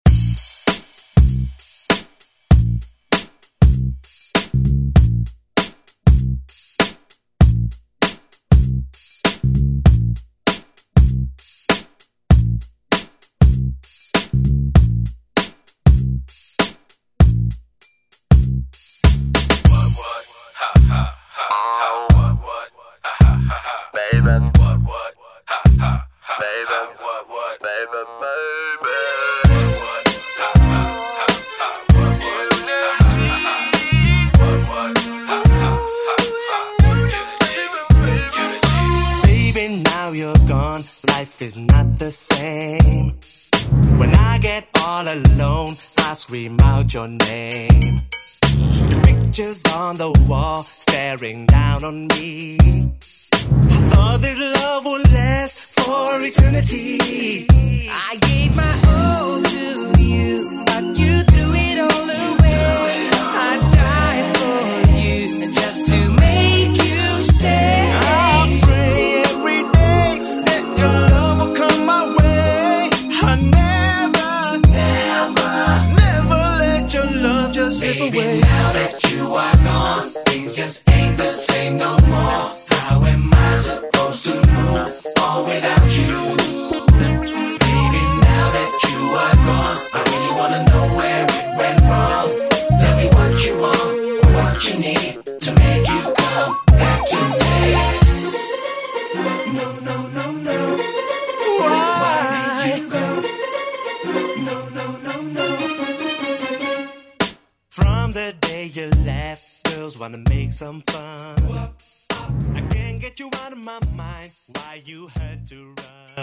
Electronic Funk Soul Disco Music
130 bpm